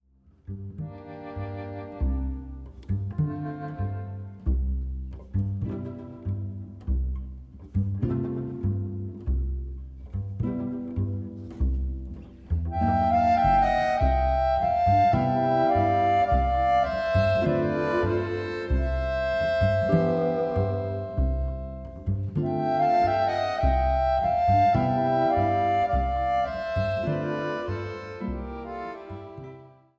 Violine
Bajan
Gitarre
Kontrabass